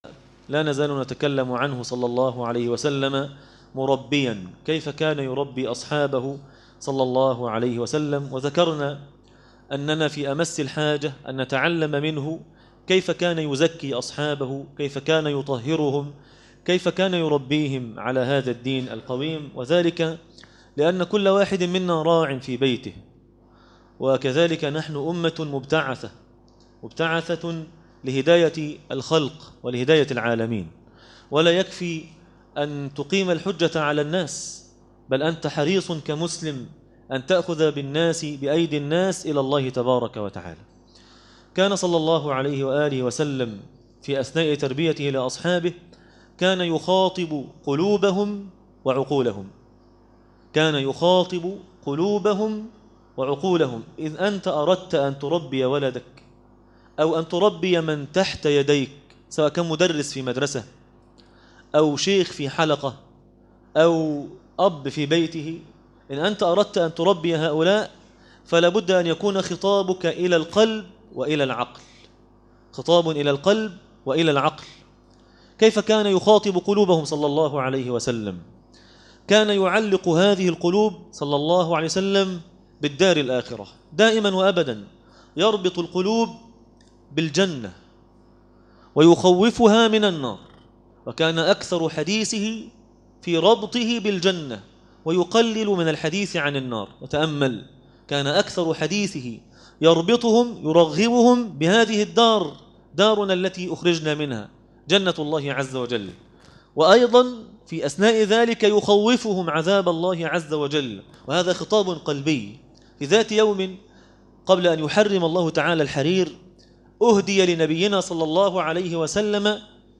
النبي (صلي الله عليه وسلم ) مربيا - الجزء الثالث- درس التراويح